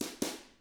146BOSSAI1-L.wav